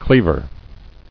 [cleav·er]